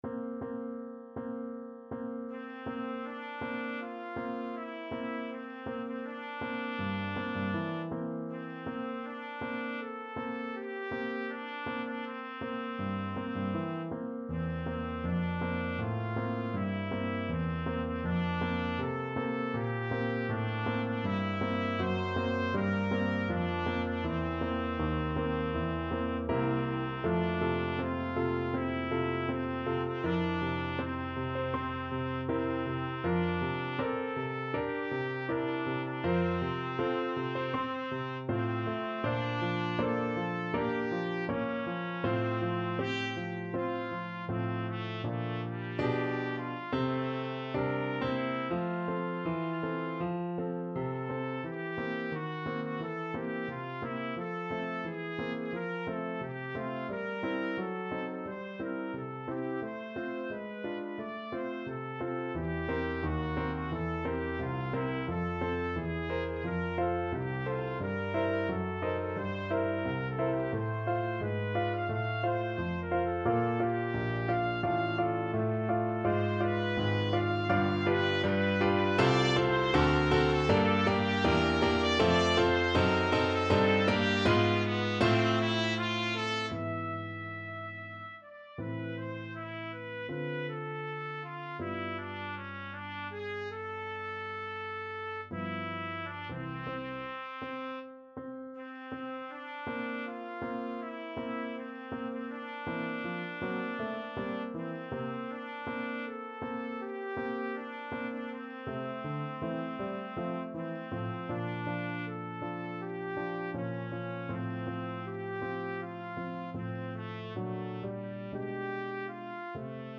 Trumpet
G4-F6
F major (Sounding Pitch) G major (Trumpet in Bb) (View more F major Music for Trumpet )
4/4 (View more 4/4 Music)
Andante espressivo
Classical (View more Classical Trumpet Music)